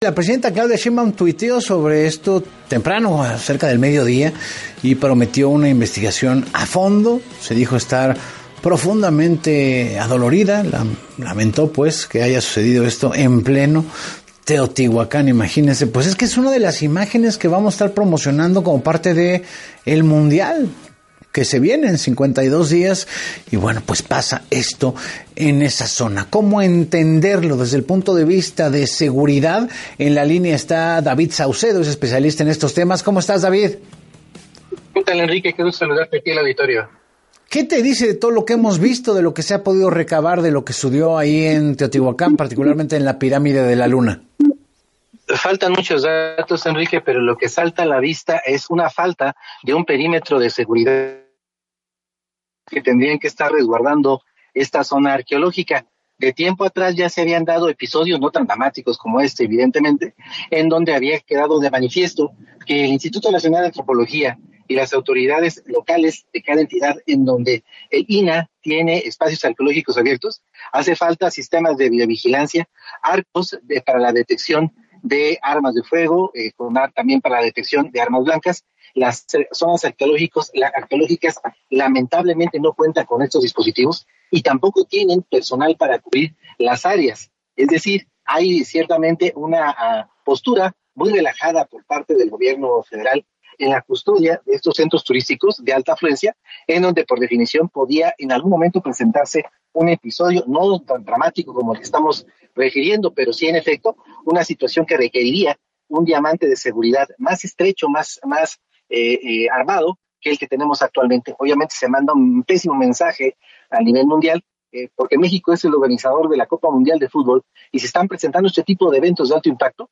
En entrevista con Enrique Hernández Alcazar el especialista en seguridad